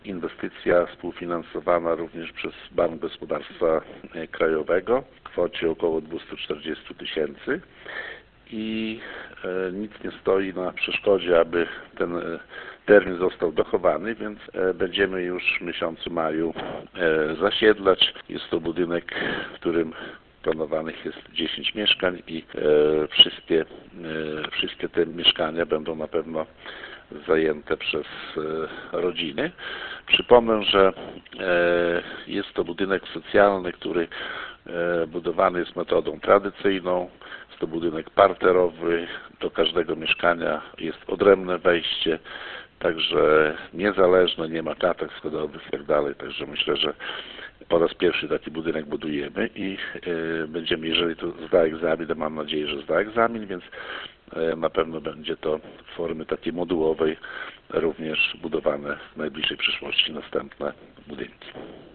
– Lokale będą miały powierzchnię od 36 do 79 m2 – mówi Wacław Olszewski, burmistrz Olecka.